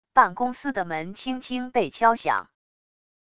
办公司的门轻轻被敲响wav音频